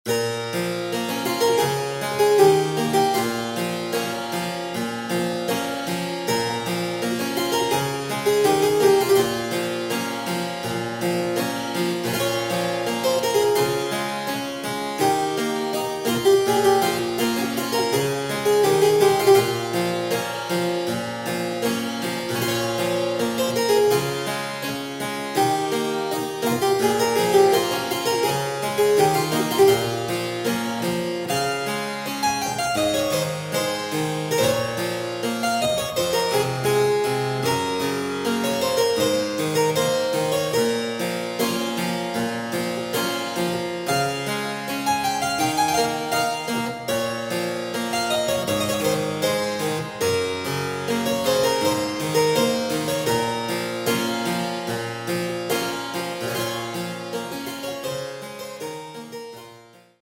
A collection of old and new music for Harpsichord.